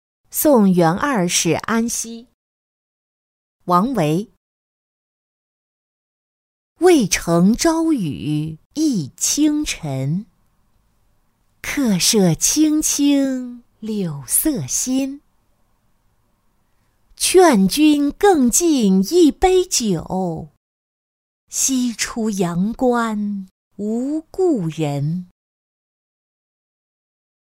送元二使安西-音频朗读